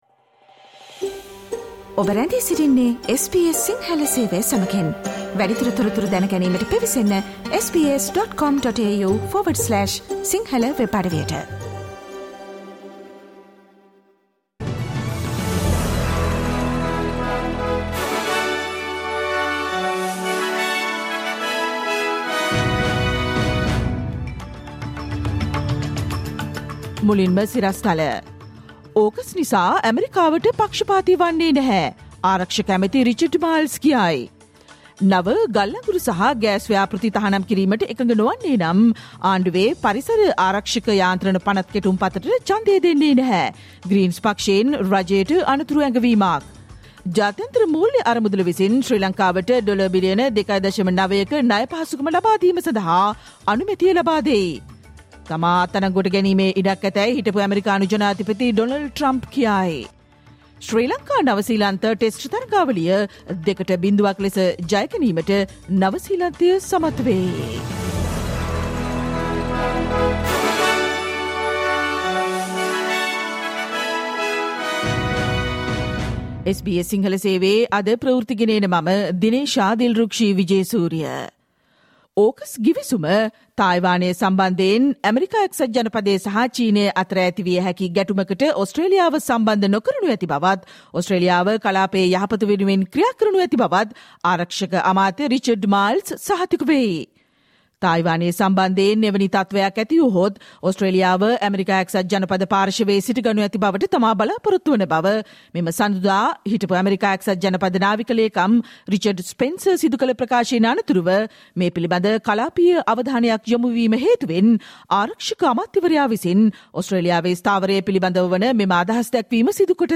Listen to the SBS Sinhala Radio news bulletin on Tuesday 21 March 2023